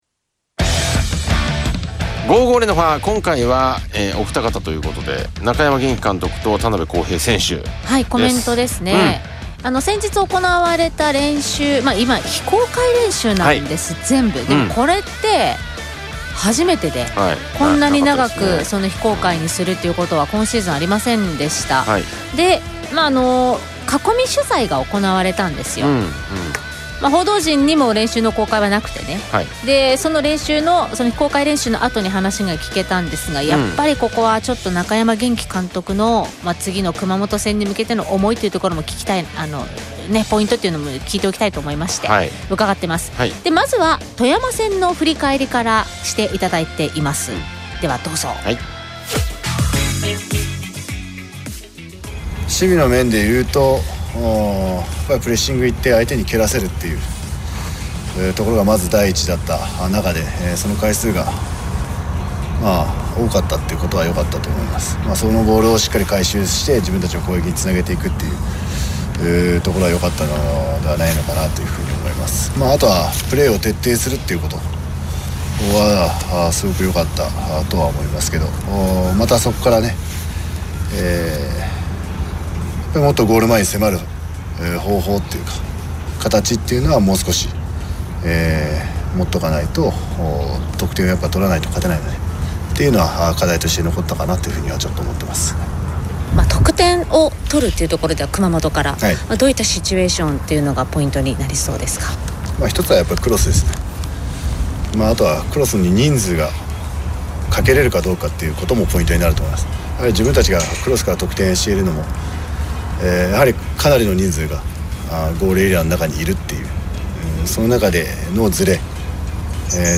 （ともに囲み取材音源）